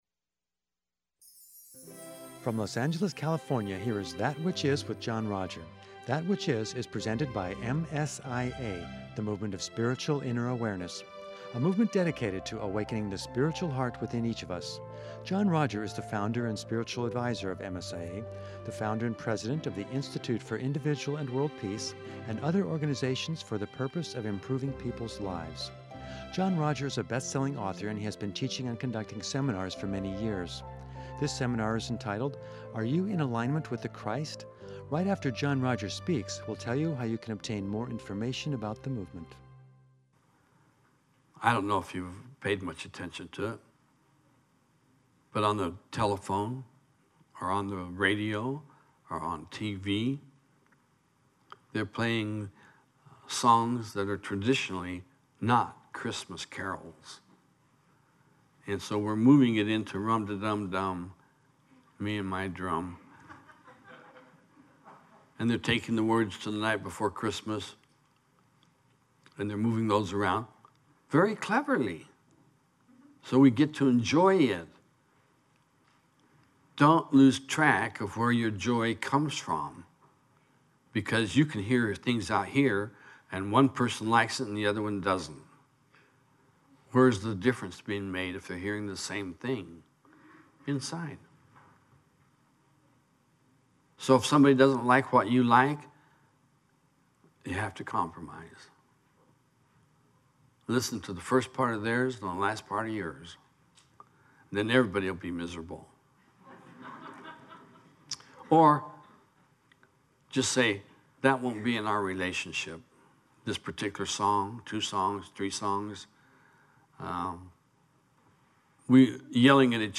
within the sweetness of part two of the seminar